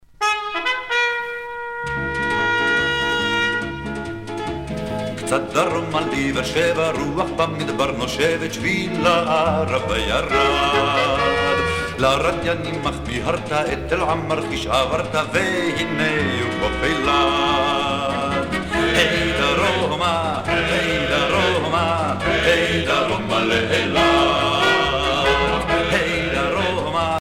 Chants patriotiques et de retour